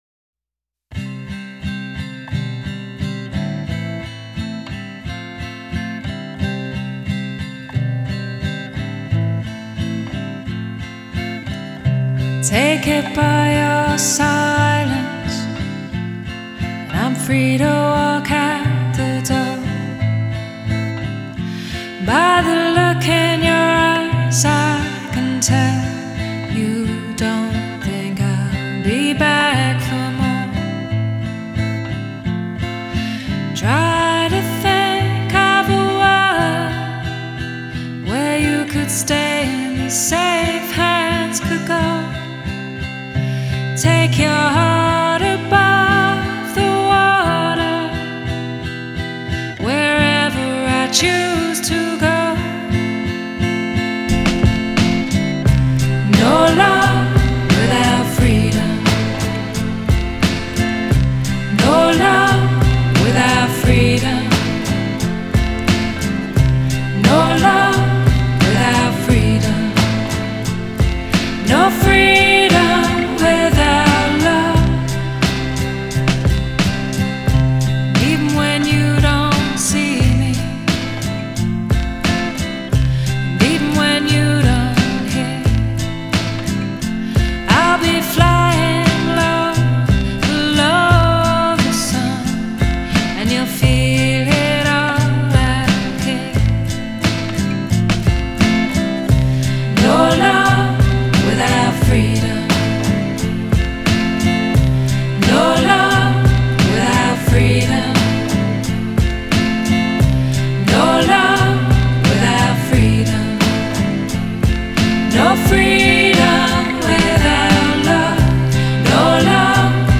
honey-voiced English singer-songwriter